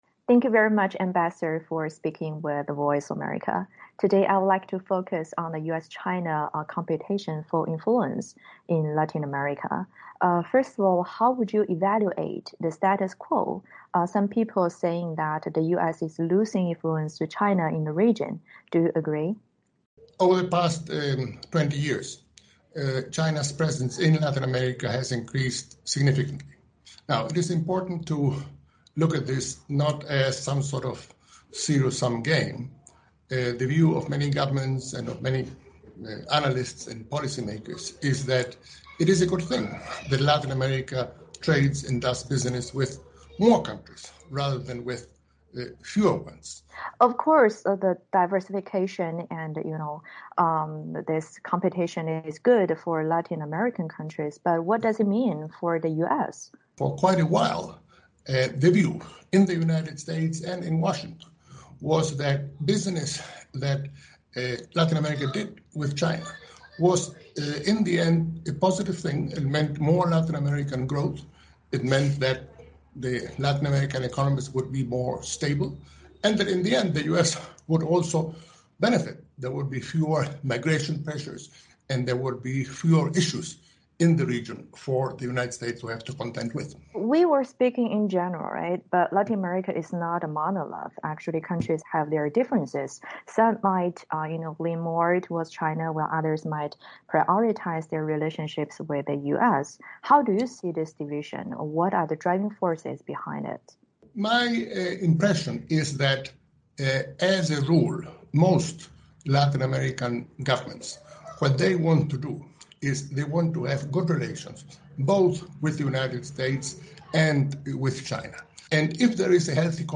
VOA专访: 专访前智利驻华大使海涅：美国应更加关注拉美面临的挑战及增长需求